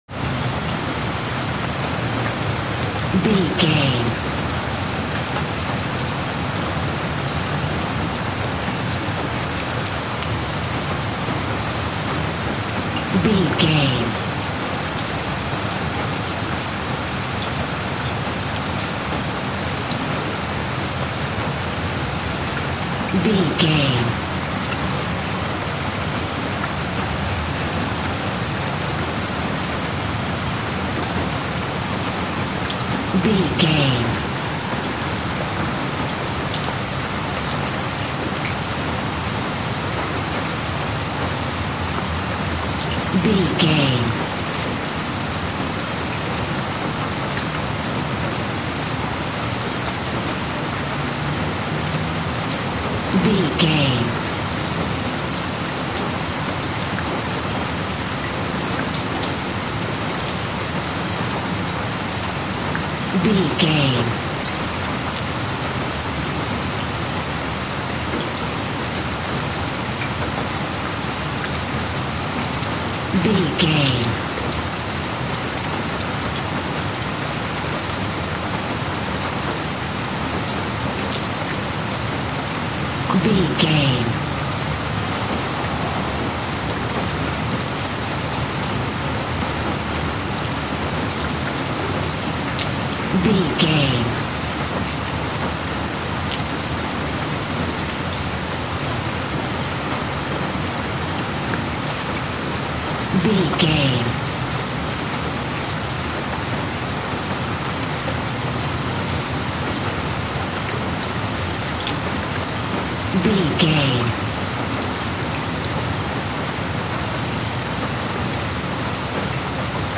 City night small rain
City night small rain 6
Sound Effects
urban
ambience